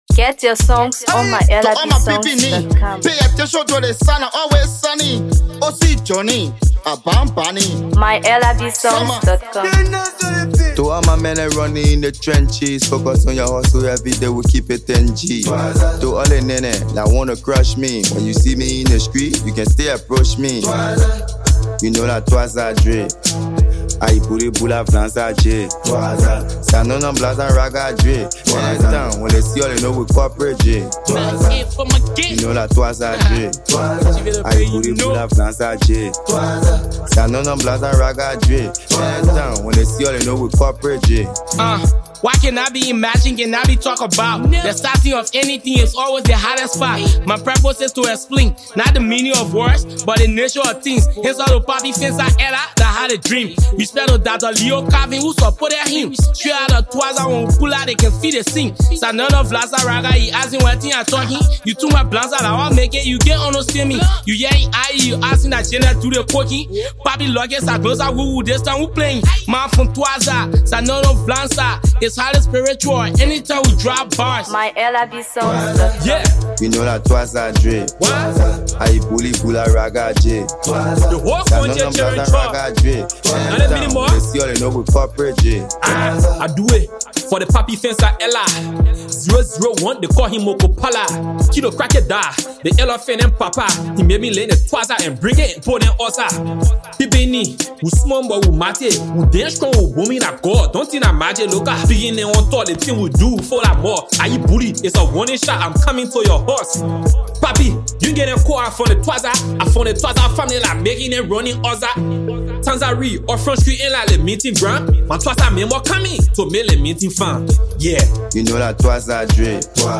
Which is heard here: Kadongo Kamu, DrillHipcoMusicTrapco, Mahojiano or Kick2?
DrillHipcoMusicTrapco